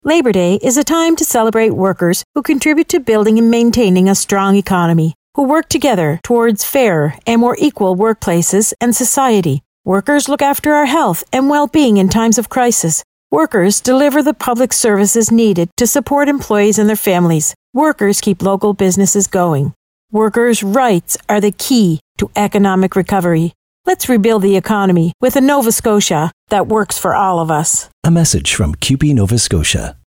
To mark Labour Day this year, CUPE Nova Scotia is running a radio ad on stations across the province.